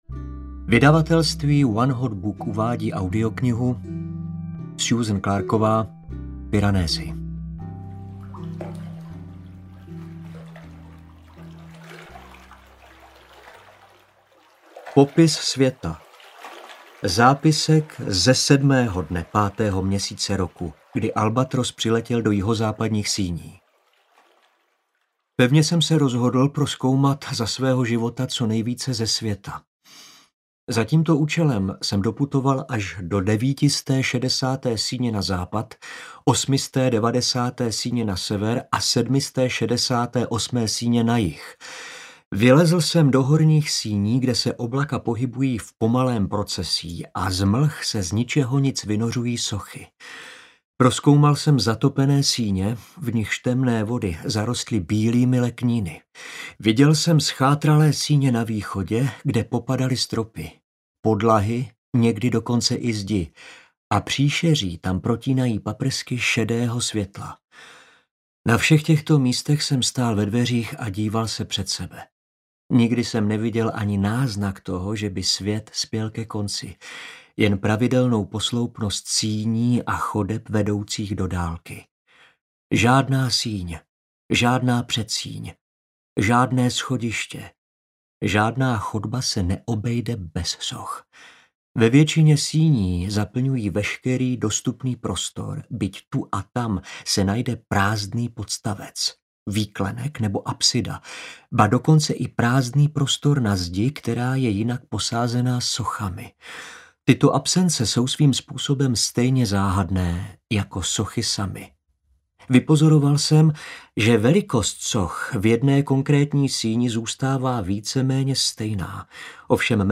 Audio knihaPiranesi
Ukázka z knihy
• InterpretJaroslav Plesl